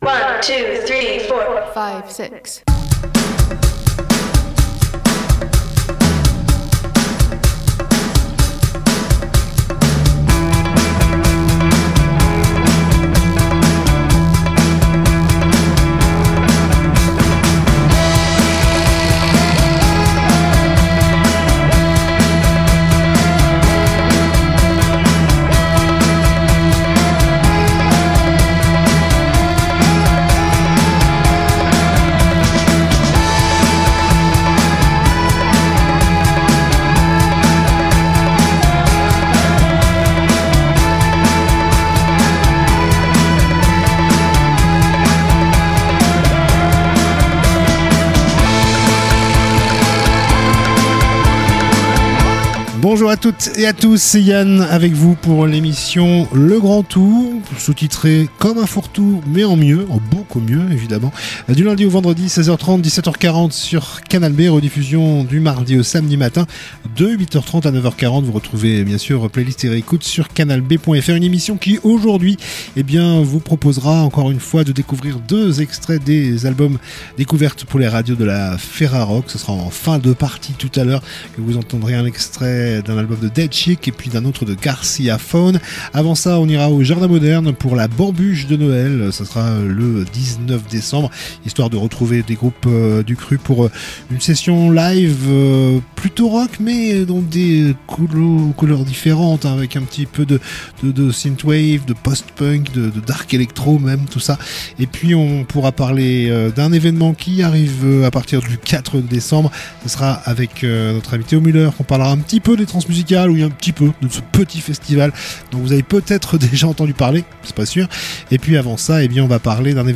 culture Discussion